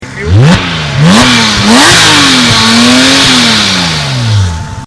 A beneficio degli assenti ... ecco la voce della belva...